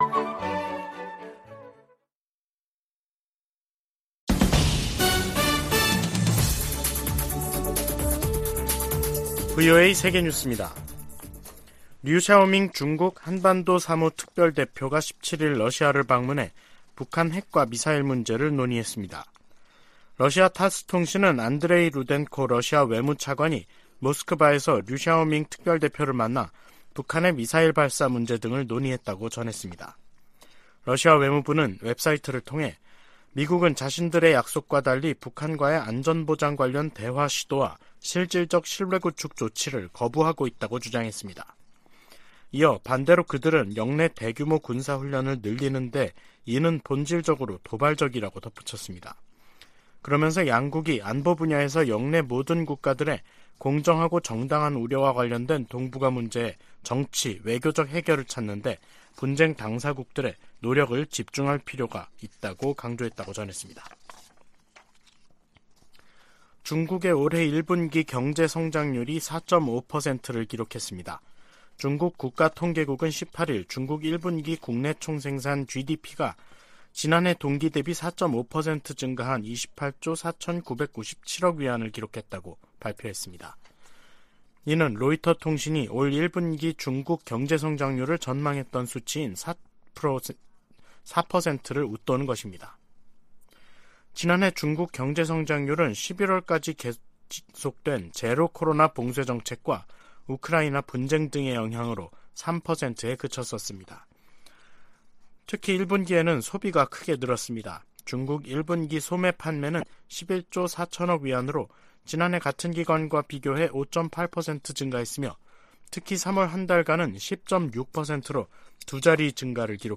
VOA 한국어 간판 뉴스 프로그램 '뉴스 투데이', 2023년 4월 18일 3부 방송입니다. 백악관은 정보당국의 도·감청 문건과 관련해 신속한 조치를 취하고 있으며, 파트너 국가들과의 신뢰는 훼손되지 않았다고 평가했습니다. 미 국방부는 도·감청 문제와 관련, 특히 한국과는 매우 좋은 관계를 유지하고 있다고 강조했습니다. 유엔 안전보장이사회가 북한의 신형 대륙간탄도미사일(ICBM) 관련 공개회의를 열고 반복 도발을 강하게 규탄했습니다.